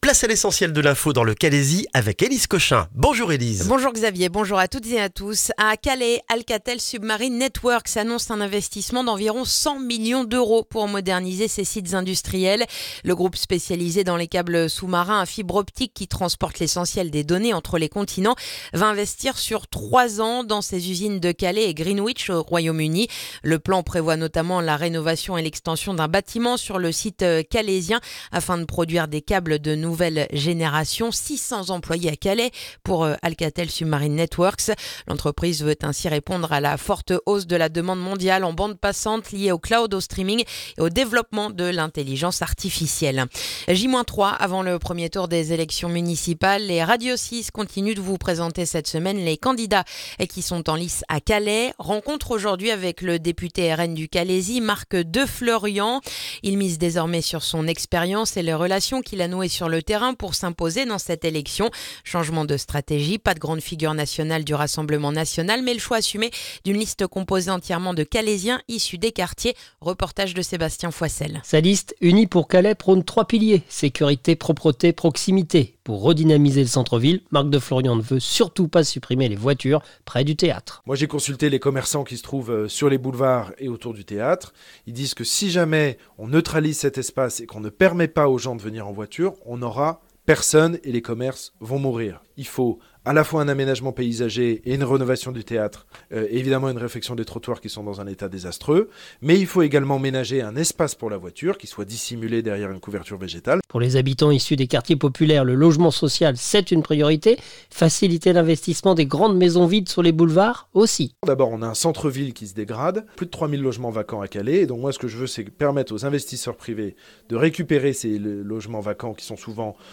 Le journal du jeudi 12 mars dans le calaisis